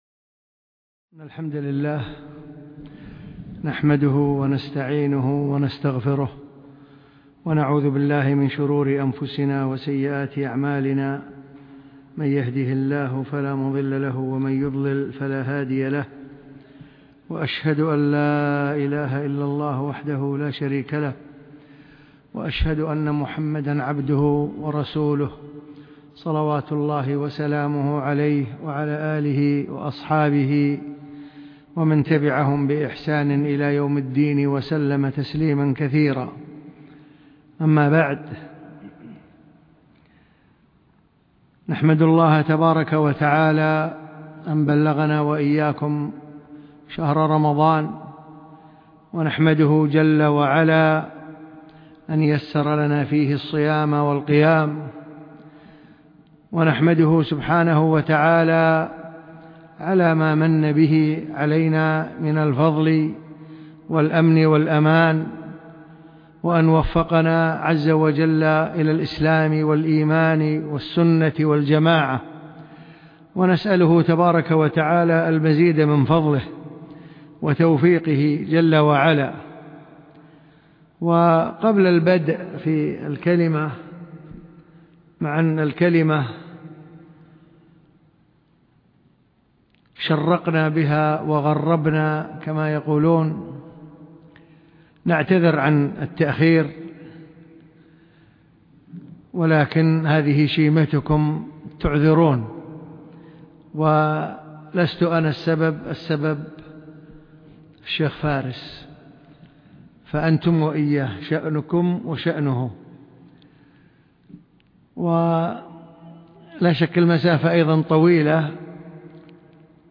لقاء مفتوح بمدينة خورفكان بإمارة الشارقة